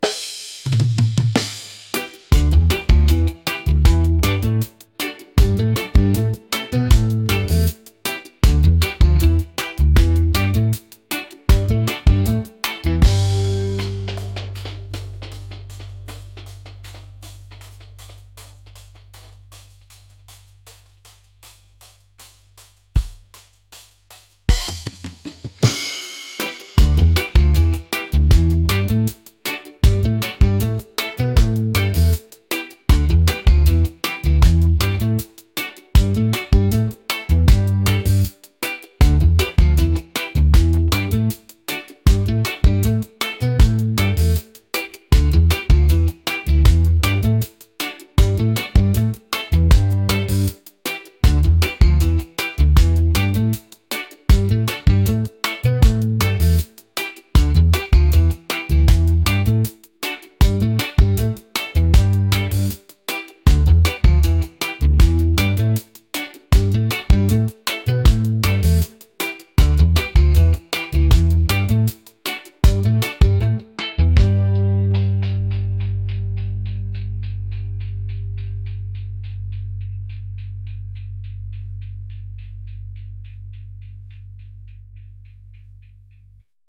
reggae | upbeat | groovy